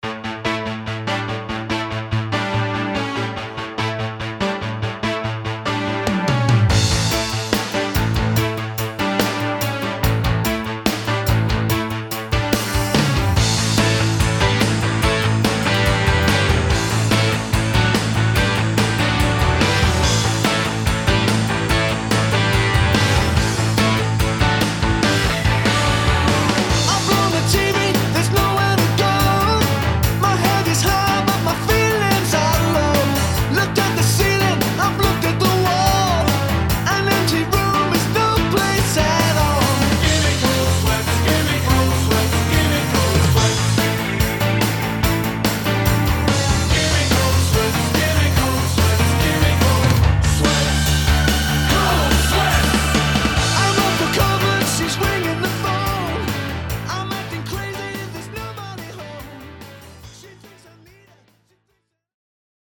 CLASSIC SONG COVERS
Backing Vocals…
Fender ‘63 Precision Bass